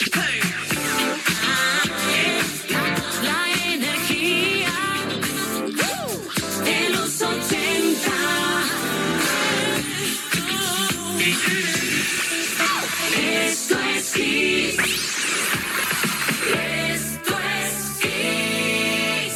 Indicatiu de l' emissora